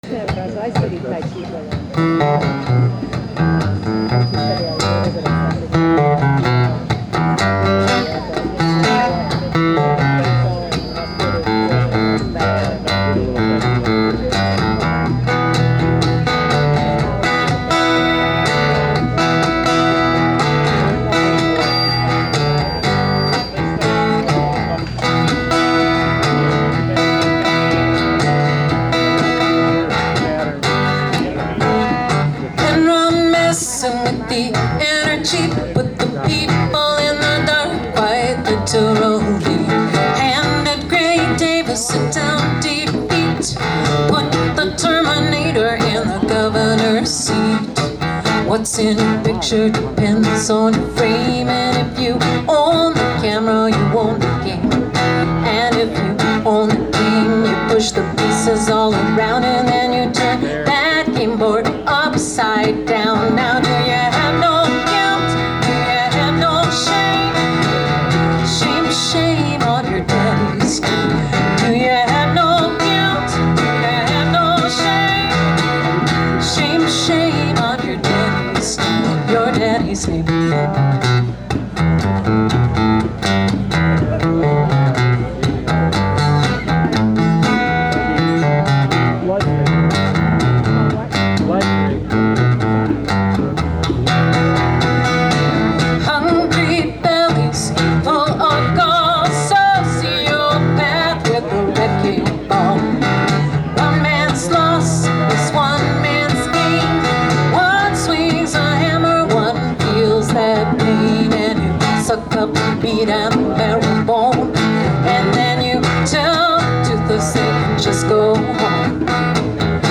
on percussion
very catchy and thought-provoking